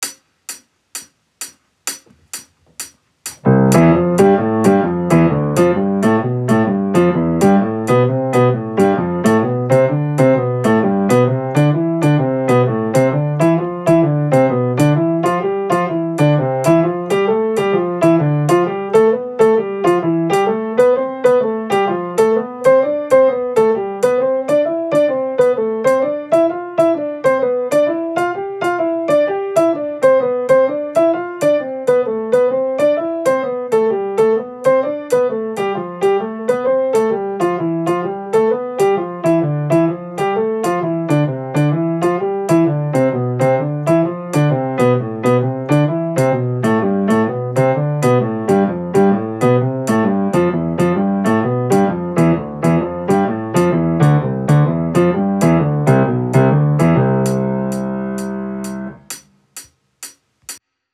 ♩ =130 （ハイハット音）